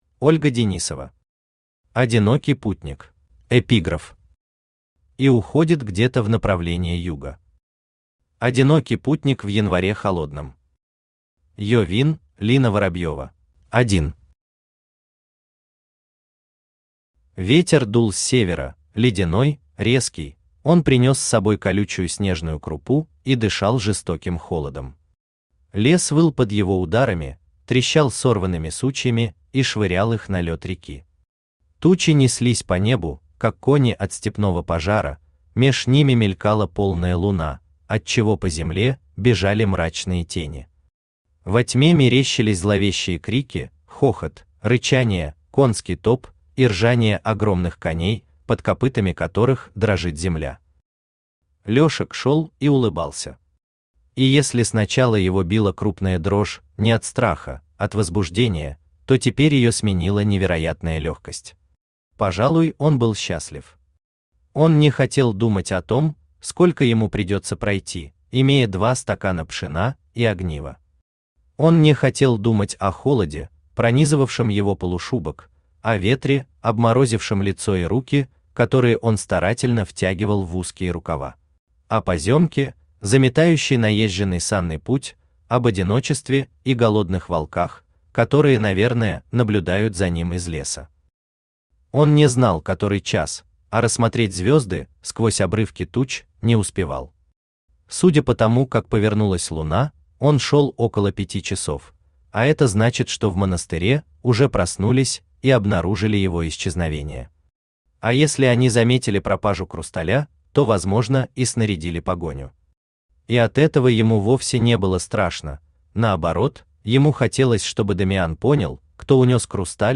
Аудиокнига Одинокий путник | Библиотека аудиокниг
Aудиокнига Одинокий путник Автор Ольга Леонардовна Денисова Читает аудиокнигу Авточтец ЛитРес.